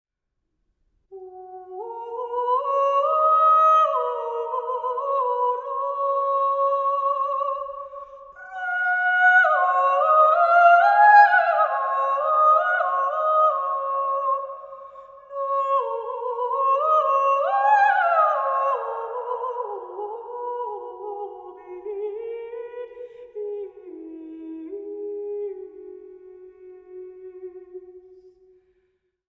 sopraano, dulcimer, sinfonia ja 5-kielinen kantele